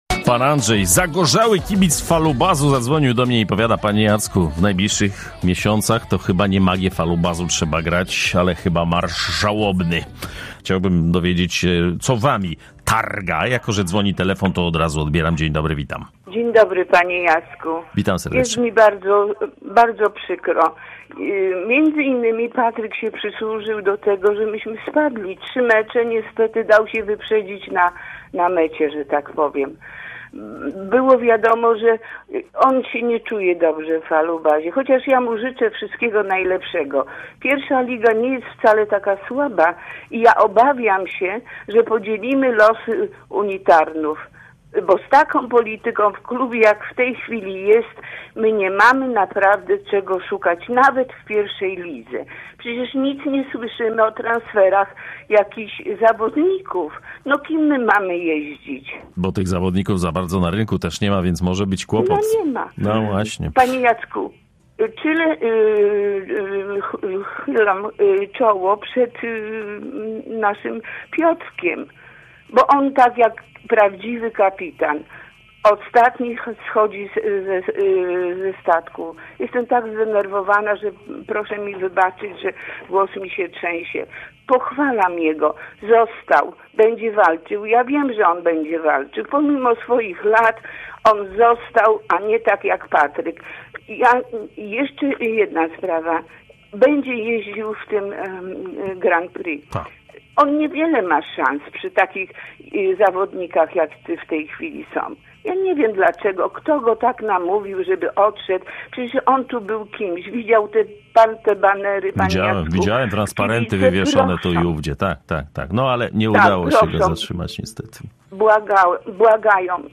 Dziś od rana pytaliśmy fanów, jak przyjmują decyzje żużlowca – ci prezentują ekstremalne emocje – od płaczu, przez złość aż po zrozumienie dla ruchu znakomitego zawodnika: